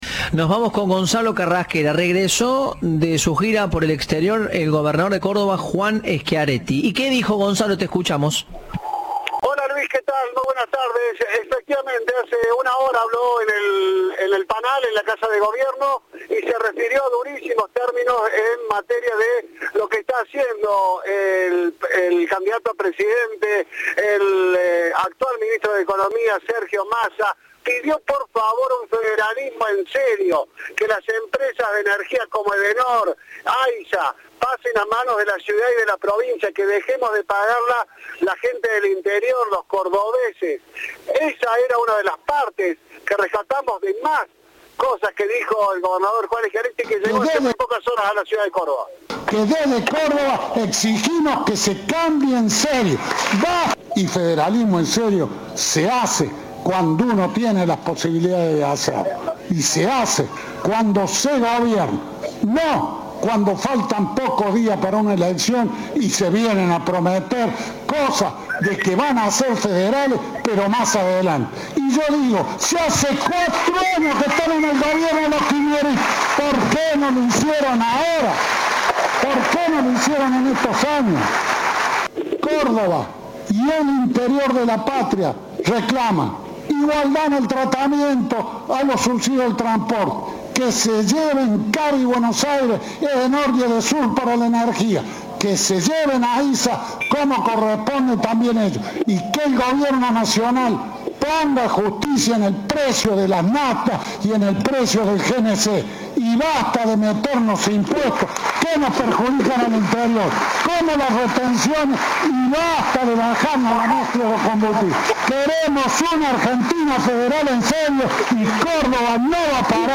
"Desde Córdoba exigimos que se cambie en serio. Federalismo en serio se hace cuando uno tiene las posibilidades de hacerlo y se hace cuando se gobierna, no cuando se está a pocos días de la elección y se vienen a prometer cosas de que van a ser federales, pero más adelante", sostuvo Schiaretti en la entrega de 400 créditos del programa MásVidaDigna.
Informe